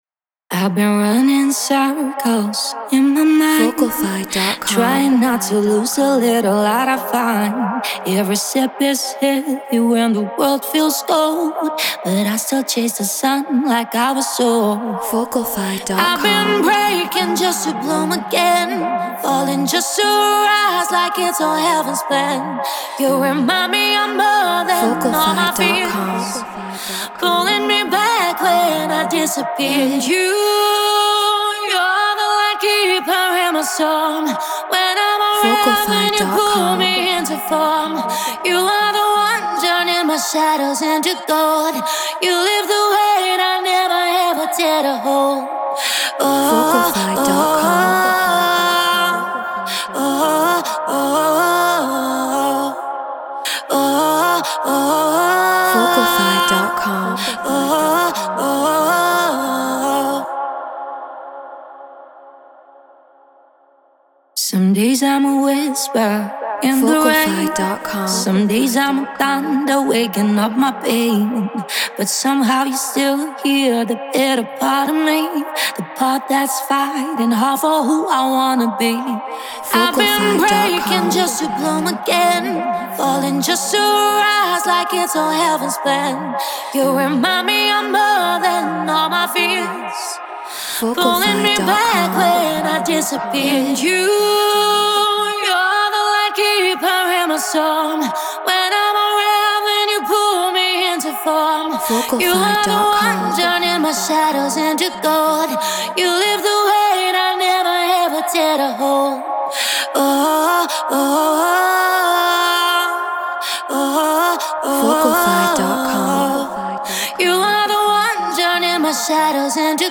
Progressive House 128 BPM C#maj
Treated Room